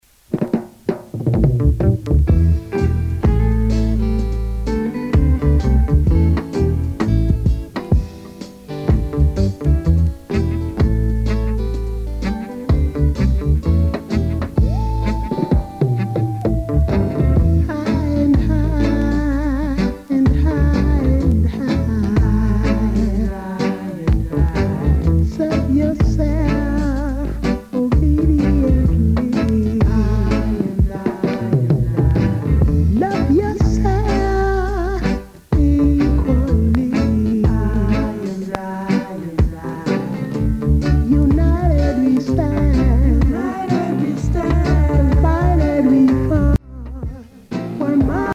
Recorded: Joe Gibbs 'N' Harry J. Studios Kgn. Ja.